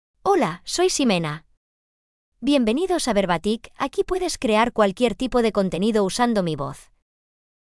Ximena — Female Spanish (Spain) AI Voice | TTS, Voice Cloning & Video | Verbatik AI
Ximena is a female AI voice for Spanish (Spain).
Voice sample
Female
Ximena delivers clear pronunciation with authentic Spain Spanish intonation, making your content sound professionally produced.